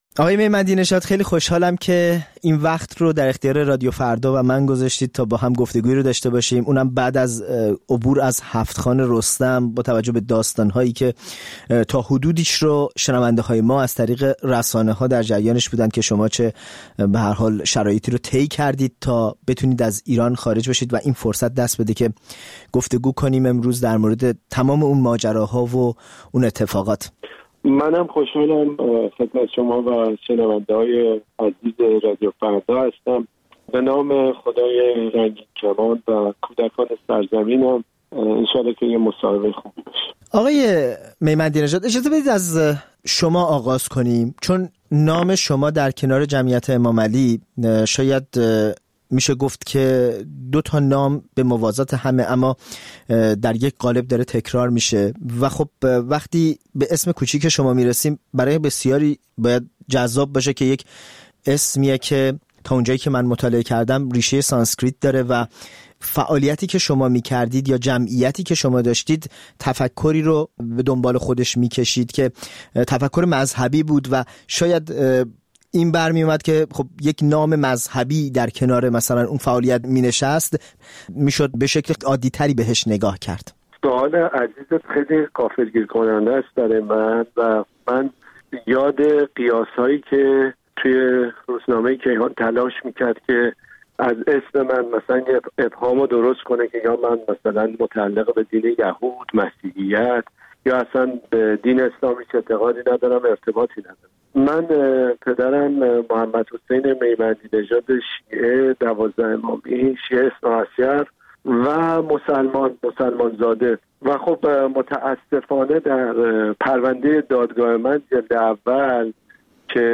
گفت‌وگوی تفصیلی